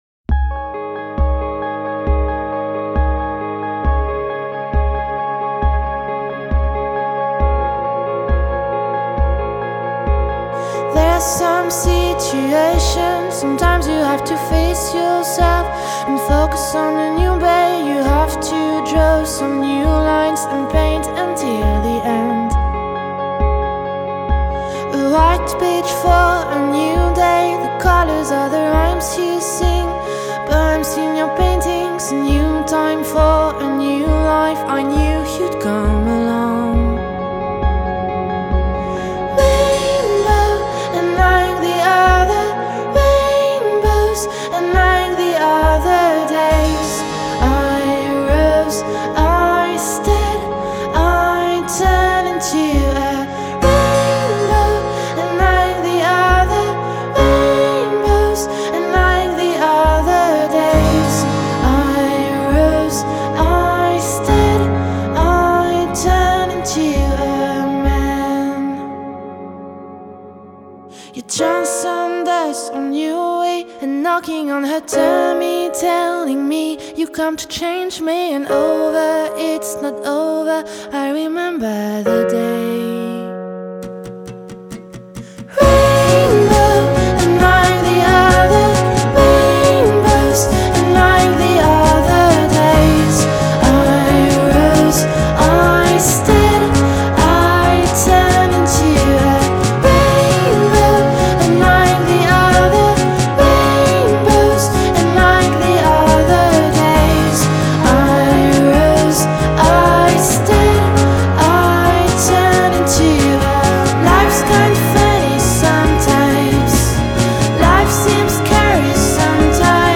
Genre: Indiepop/Piano Rock/Female Vocal